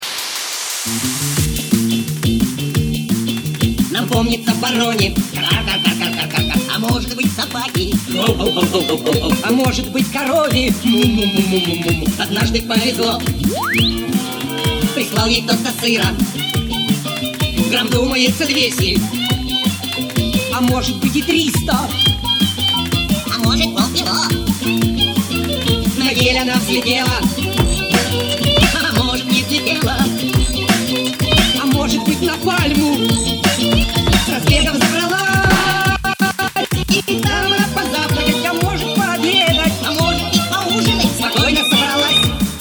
DnB Remix